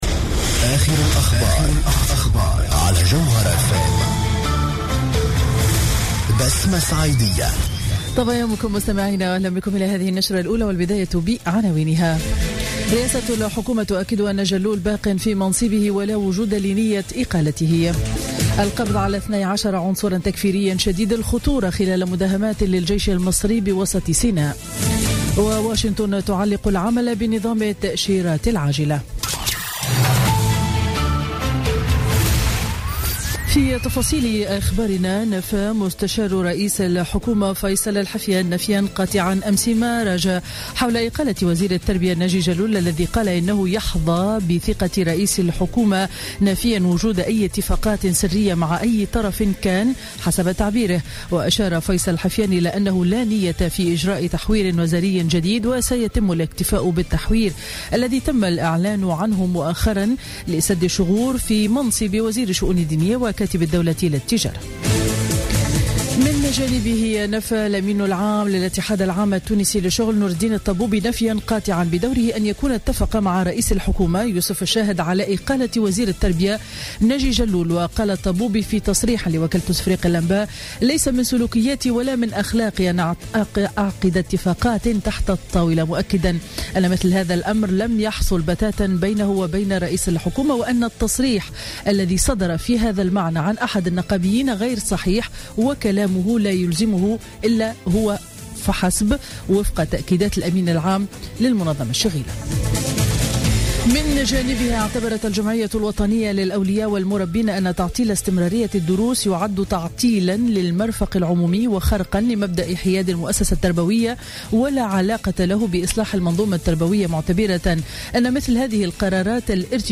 نشرة أخبار السابعة صباحا ليوم الأحد 5 مارس 2017